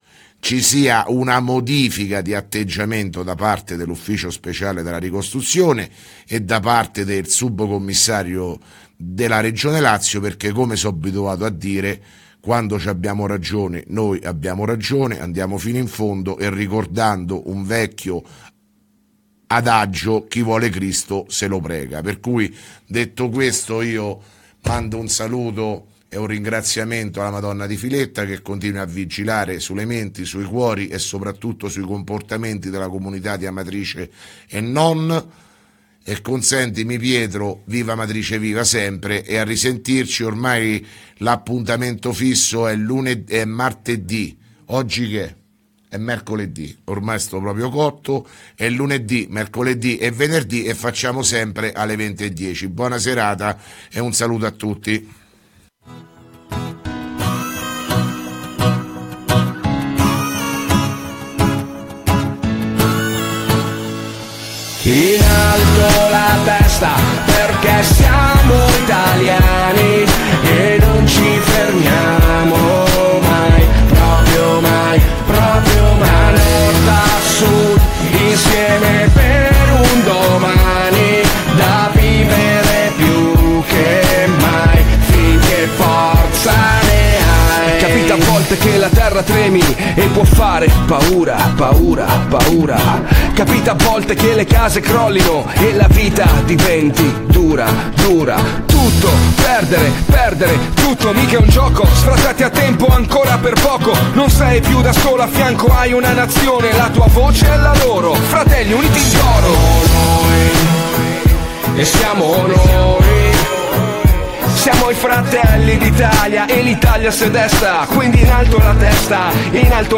Di seguito il messaggio audio del Sindaco Sergio Pirozzi del 20 Settembre 2017